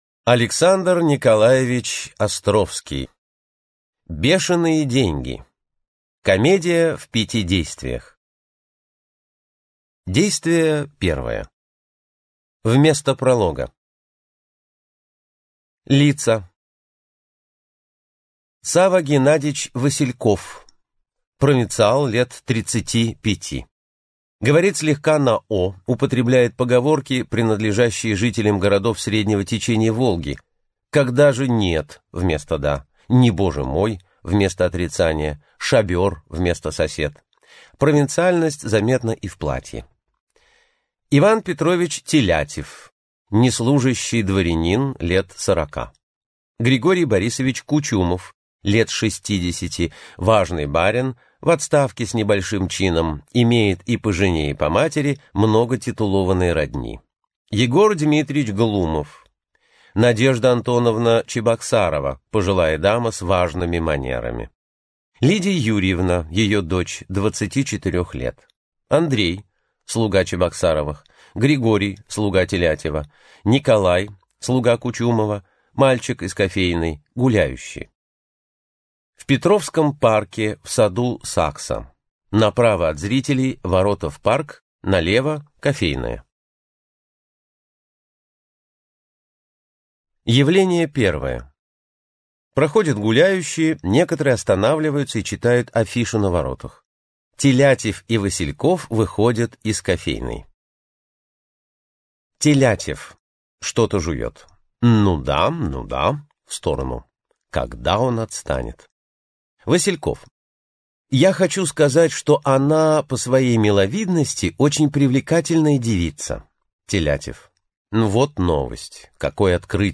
Аудиокнига Бешеные деньги | Библиотека аудиокниг